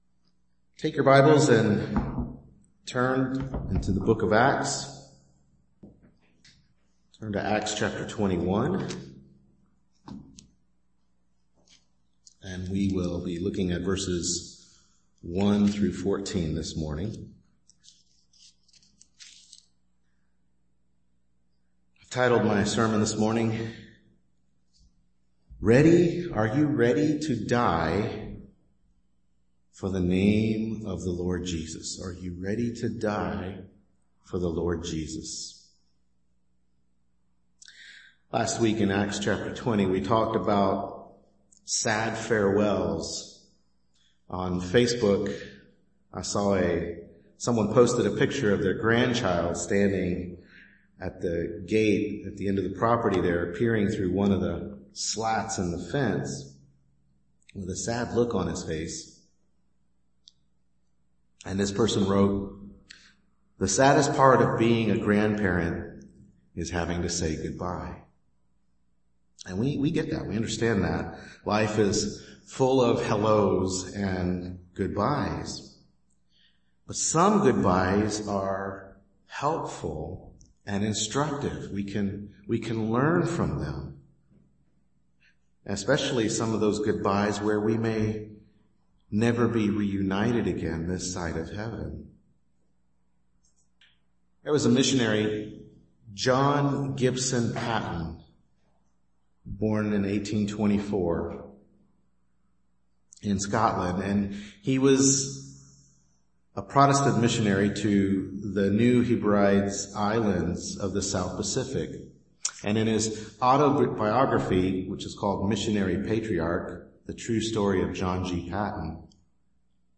Acts 21:1-14 Service Type: Morning Worship Service Bible Text